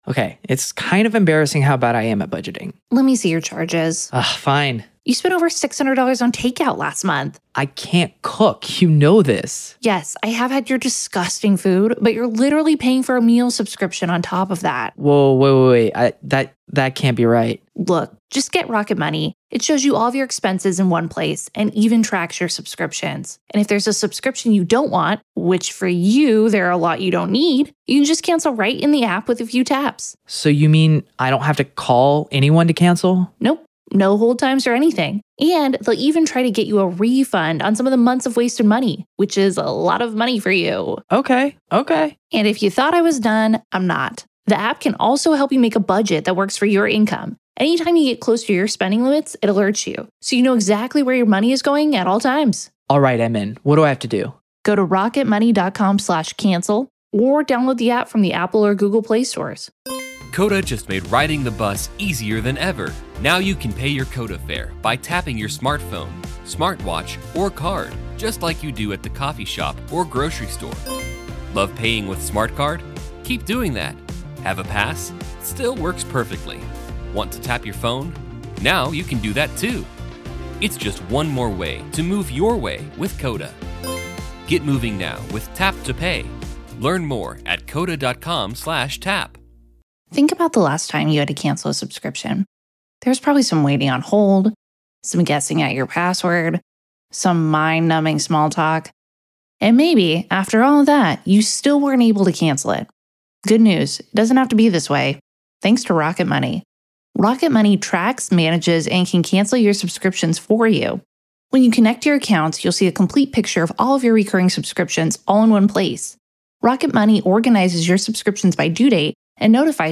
RAW COURT AUDIO: Karen Read’s Legal Battle Intensifies as Courtroom Tensions Rise-PART 4